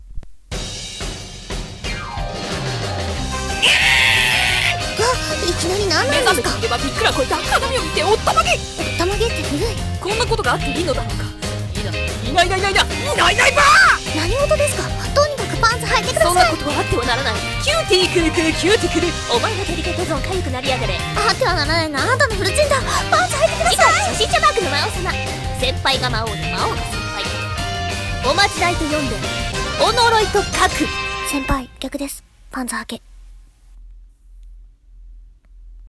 架空アニメの次回予告(後輩魔族版)